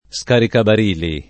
vai all'elenco alfabetico delle voci ingrandisci il carattere 100% rimpicciolisci il carattere stampa invia tramite posta elettronica codividi su Facebook scaricabarili [ S karikabar & li ] s. m. («gioco») — nel sign. fig. (scherz.